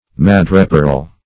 Madreperl \Ma"dre*perl\, n.